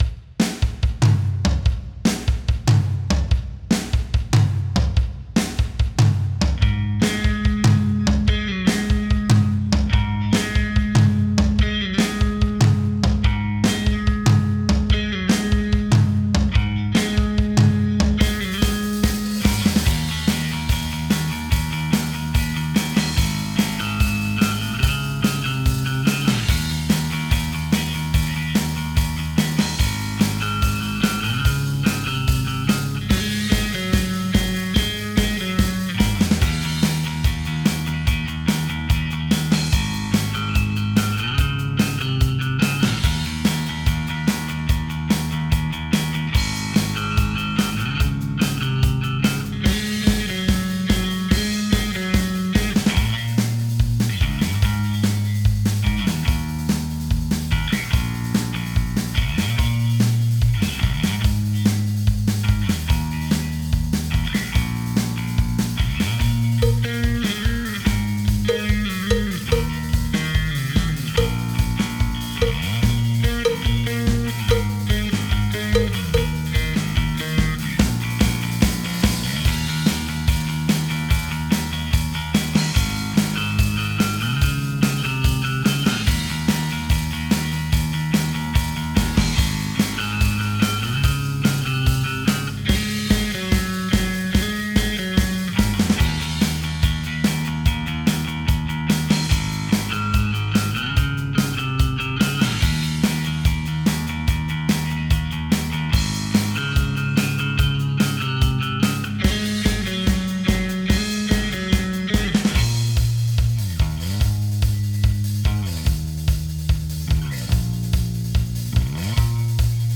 BPM : 145
Tuning : Eb
Without vocals
Based on the studio and Dortmund live version